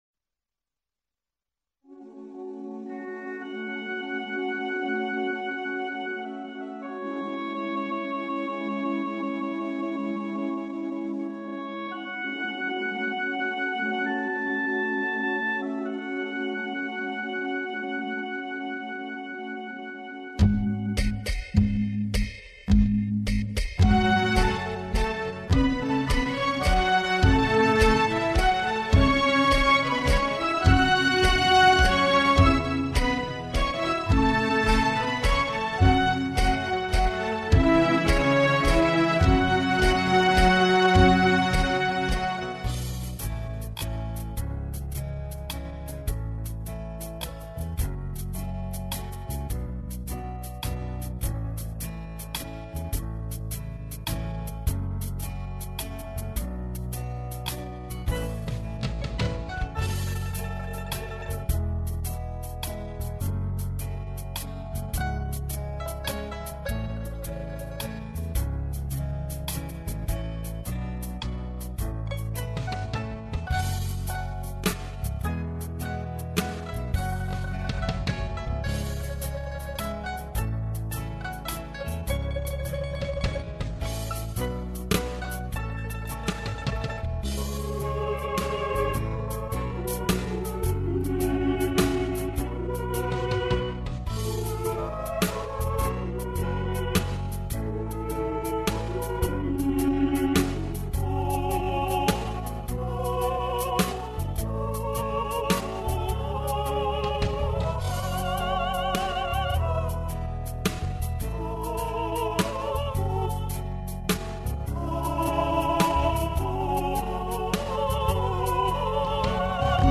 无 调式 : 降B 曲类